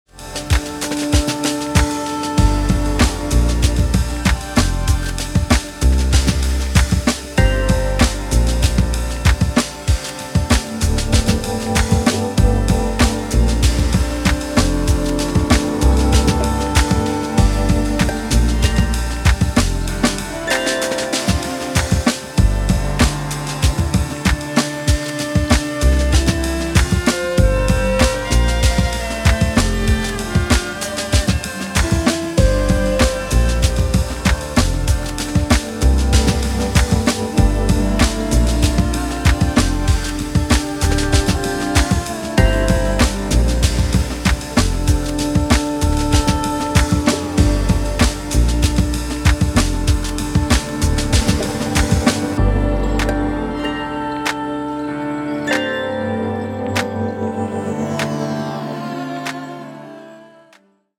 浮遊感や中毒性、温かみを備えたナイスな一枚に仕上がっています。